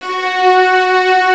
Index of /90_sSampleCDs/Roland LCDP13 String Sections/STR_Combos 2/CMB_mf Strings